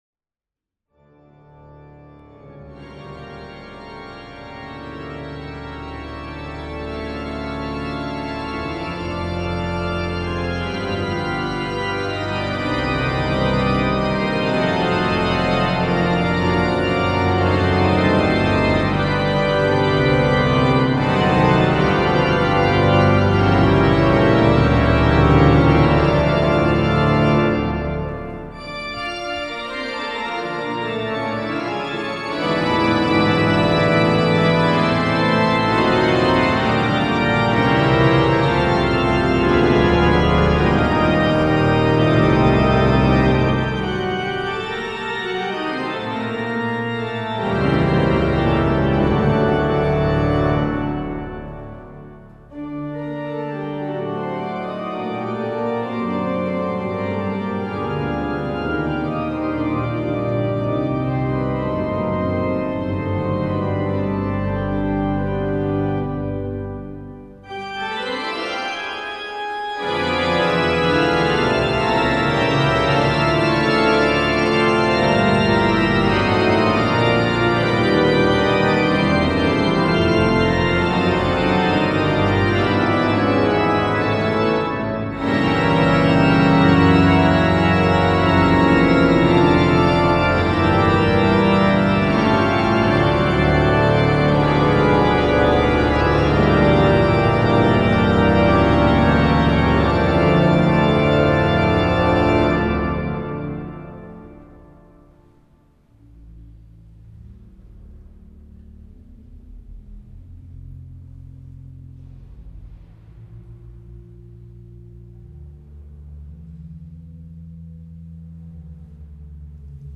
Aktualizace: koncert byl naprosto skvělý a nástroj také!
koncert Pražského jara 2014 - ukázka
Zveme Vás na koncert na obnovené varhany u sv.Ignáce v Praze 2, který se uskuteční v rámci Mezinárodního hudebního festivalu PRAŽSKÉ JARO.